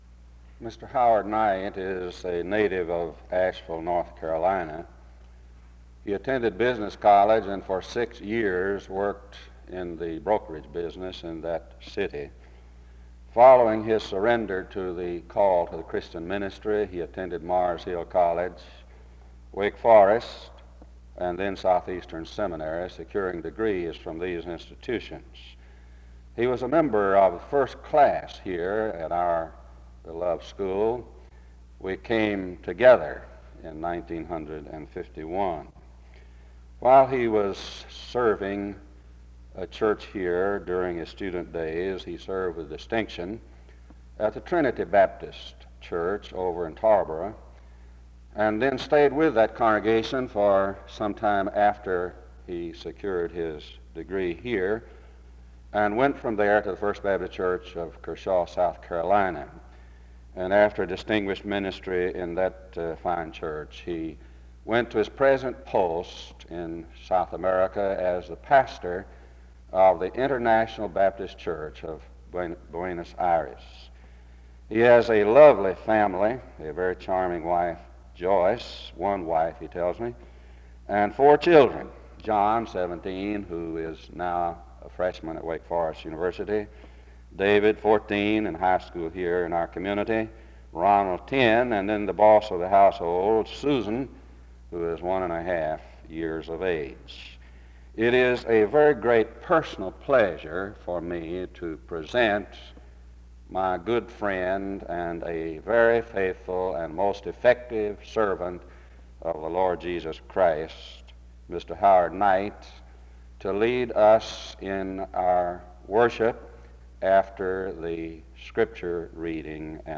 a scripture reading, and a prayer (0:00-6:20).
SEBTS Chapel and Special Event Recordings - 1970s